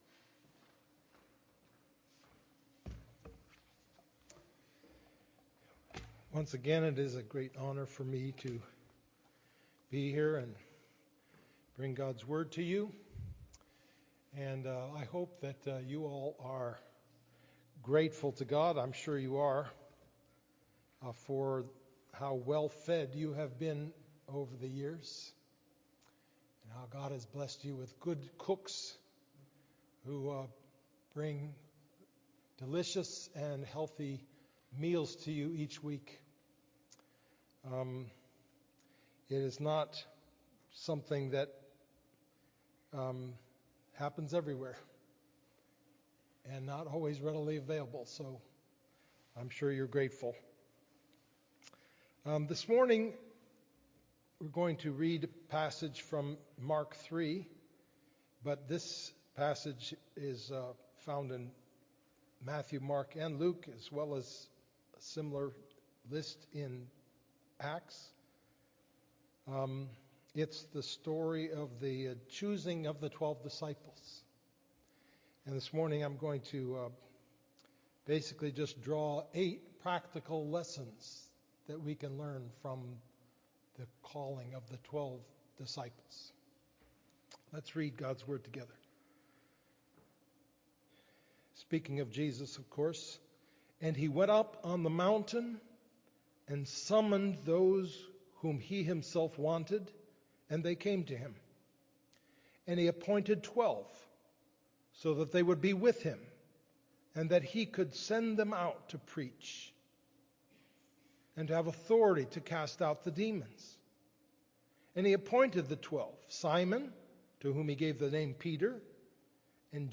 Jesus Appoints His Apostles: Sermon on Mark 3:13-19a - New Hope Presbyterian Church
october-5-2025-sermon-only.mp3